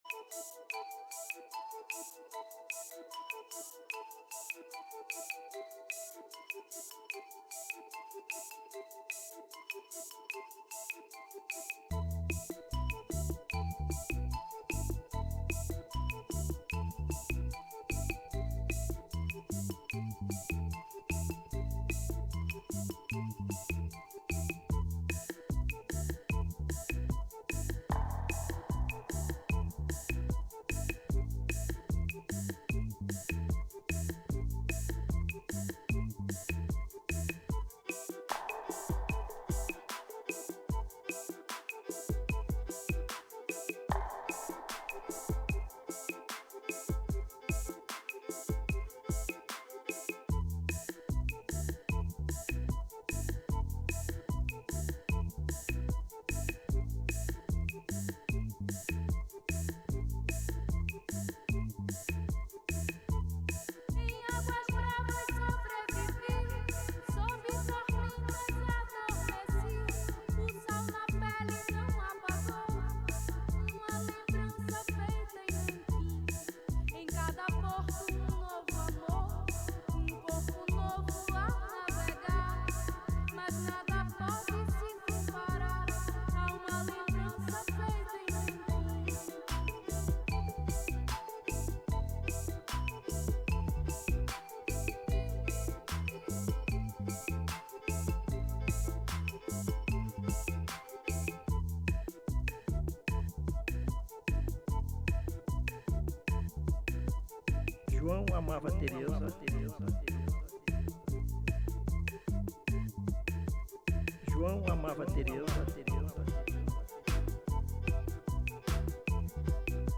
DJ, produtor, compositor, designer e escritor.